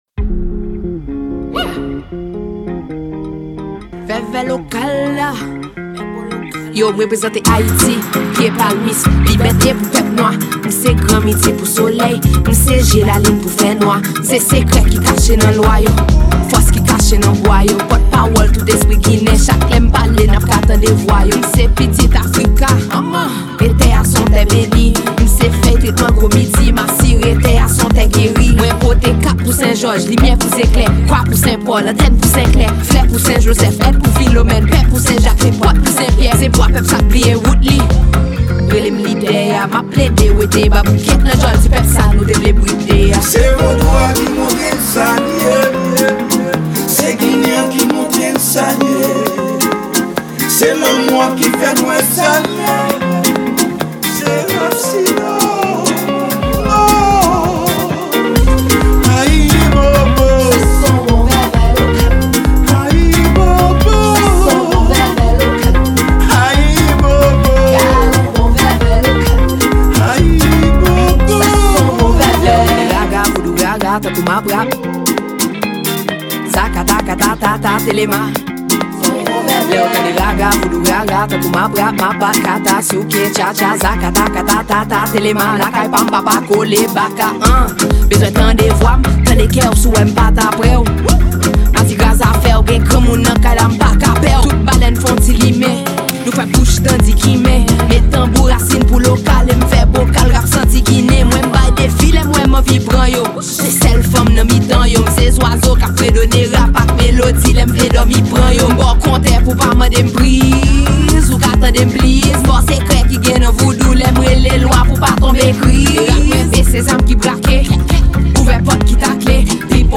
Genre: RACINE.